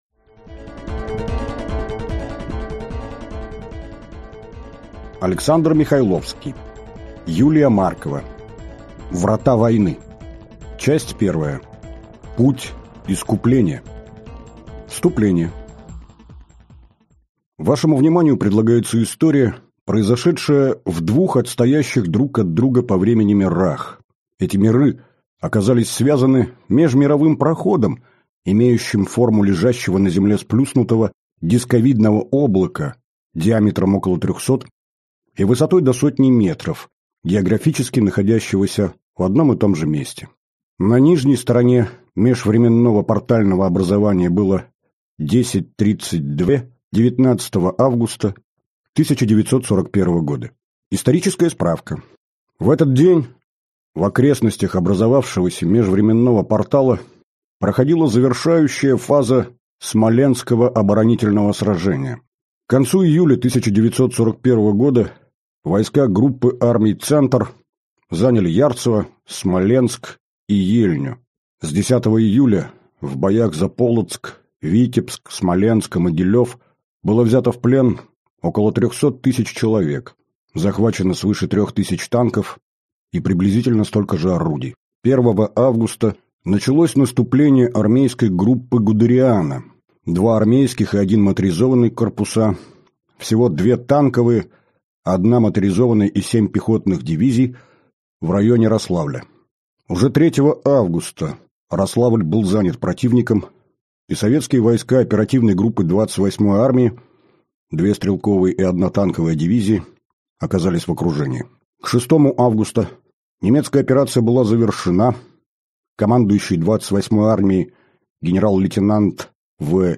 Аудиокнига Врата войны | Библиотека аудиокниг